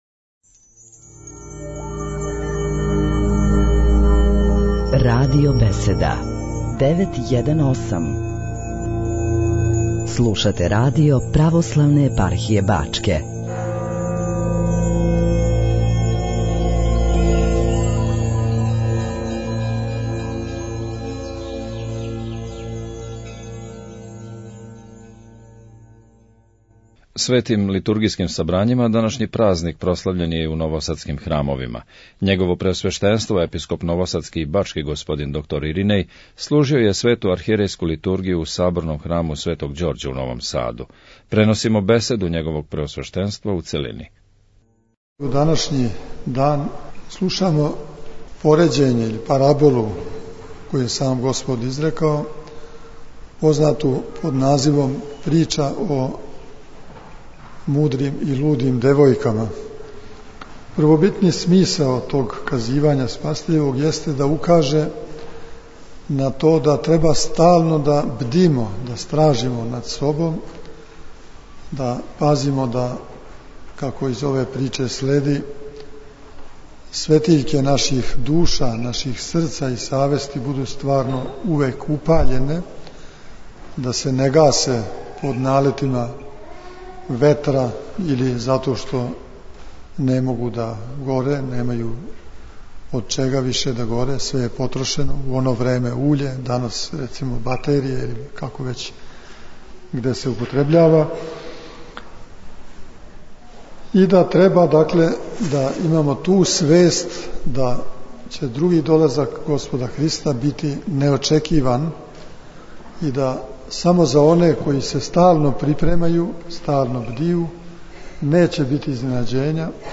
Нови Сад - Епископ бачки Господин Иринеј служио је на данашњи велики празник свету архијерејску Литургију у новосадском Саборном храму.